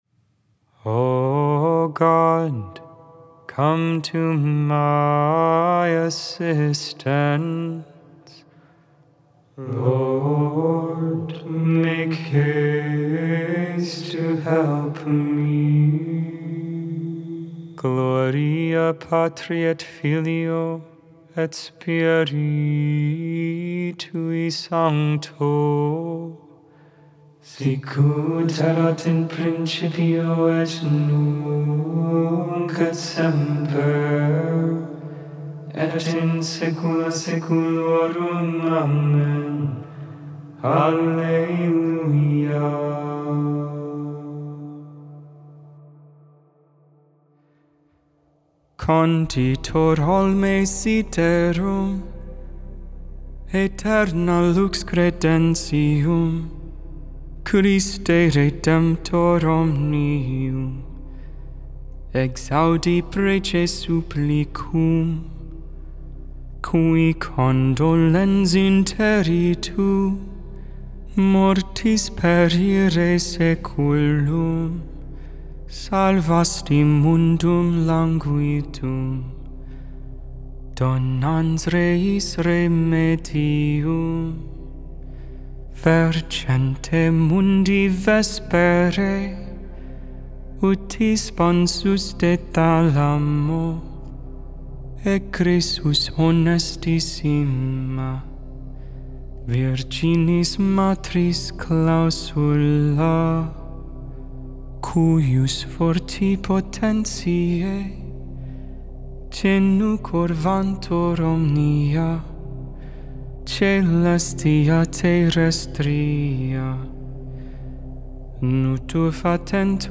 Vespers, Evening Prayer for the 2nd Sunday in Advent, December 8th, 2024.